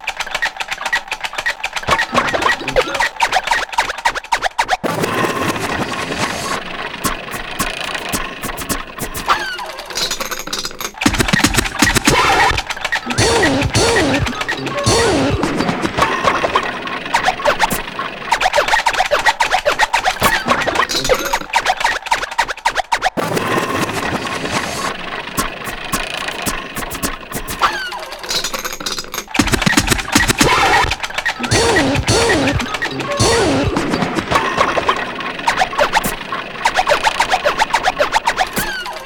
repetitivo
ritmo
sintetizador